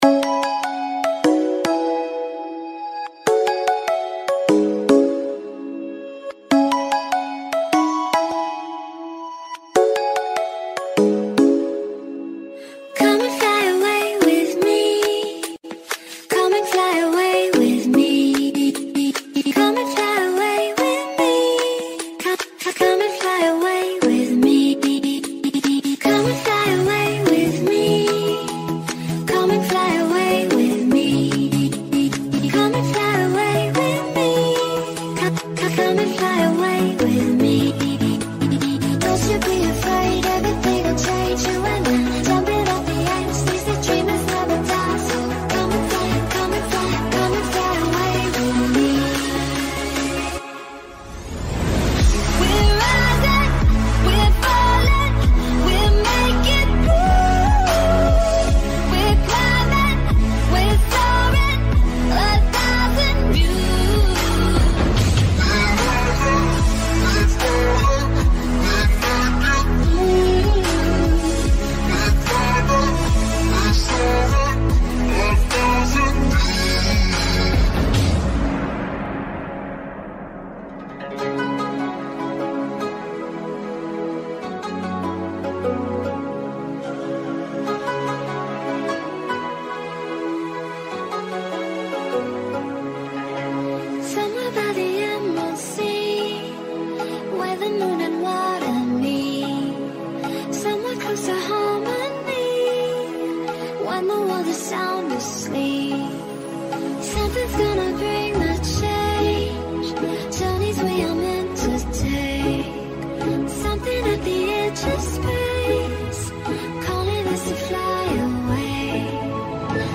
musicas-eletronicas-com-grave-2021-musicas-eletronicas-remix-2021-1-.mp3